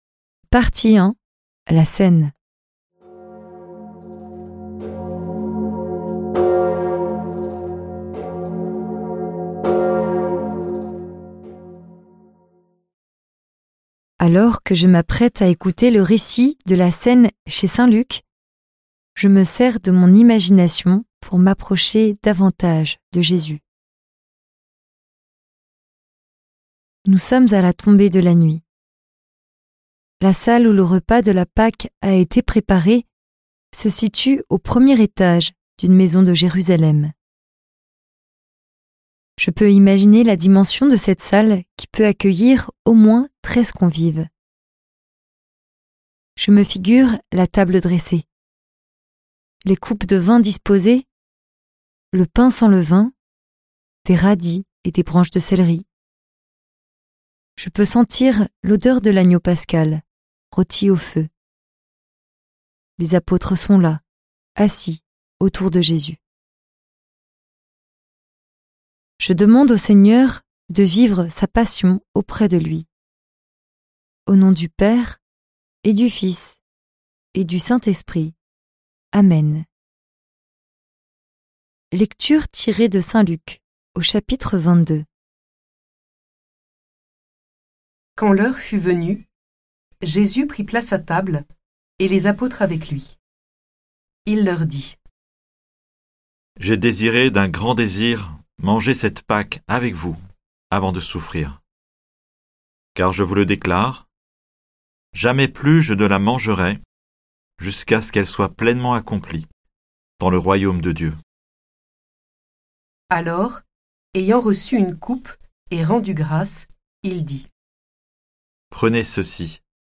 Méditation guidée suivant un livre biblique, une encyclique, des psaumes, ou un thème de la vie chrétienne.